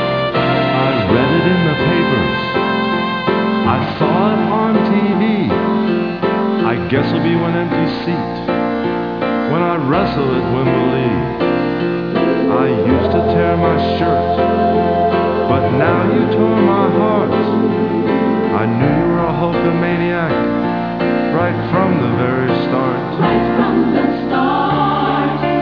Finally, there’s the touching and soulful tribute,
Casio keyboard